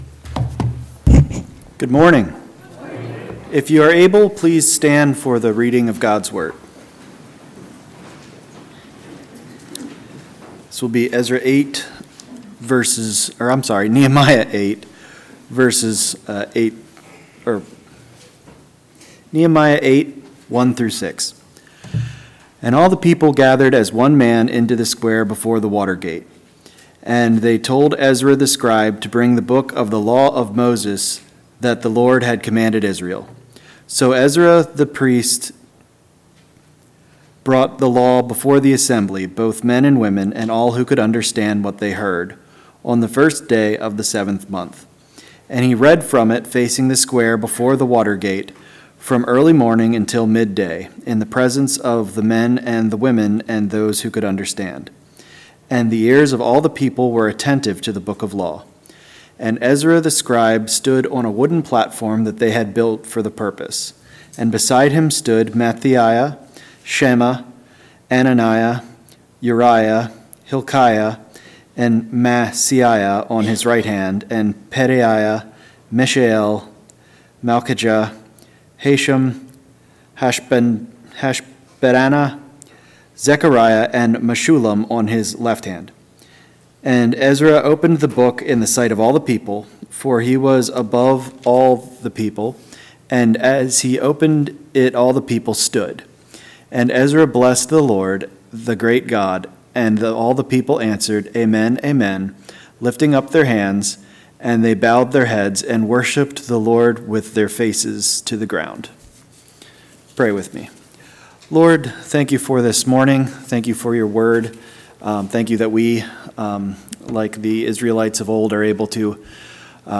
A message from the series "February 2026."